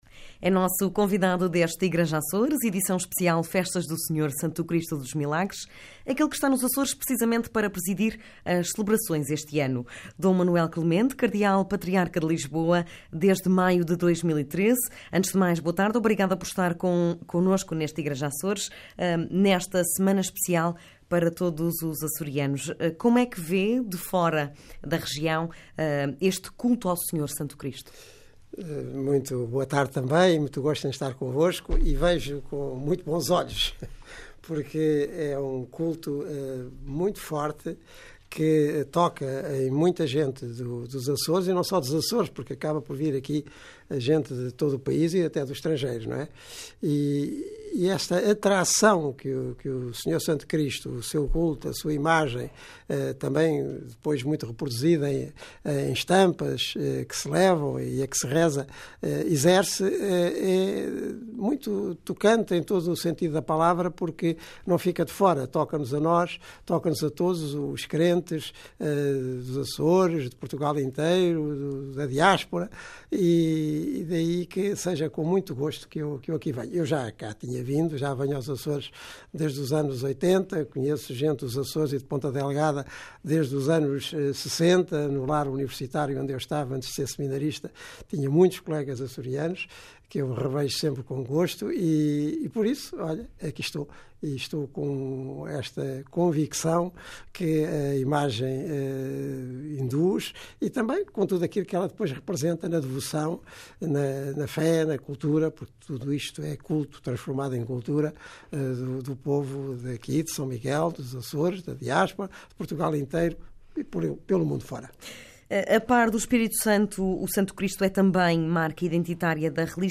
D. Manuel Clemente é o presidente da Festa do Senhor Santo Cristo e numa entrevista exclusiva ao Igreja Açores, programa de rádio e site, fala sobre a religiosidade do povo açoriano, e dos temas que estão na ordem do dia como a eutanásia, a família e os jovens